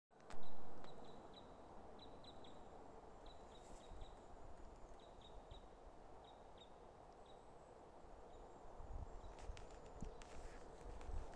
Krustknābis Loxia sp., Loxia sp.
Administratīvā teritorijaSmiltenes novads
StatussDzirdēta balss, saucieni